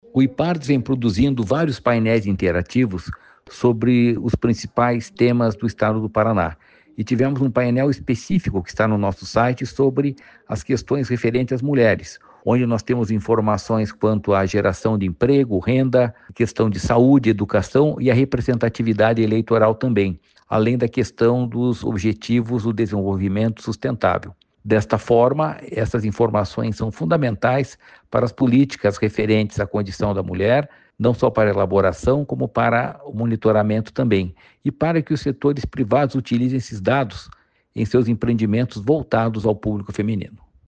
Sonora do presidente do Ipardes, Jorge Callado, sobre o Painel Mulheres do Paraná